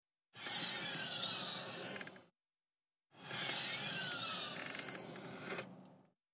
Звуки багажника
Звук механизма в багажнике (стойки), который открывает его плавно (00:06)